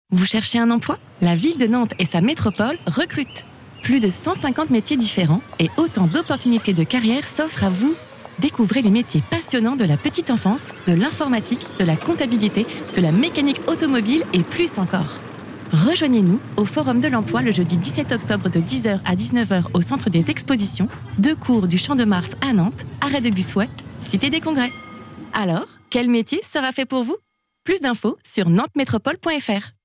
Spot radio Nantes Métropôle
Voix off